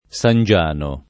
Sangiano [ S an J# no ]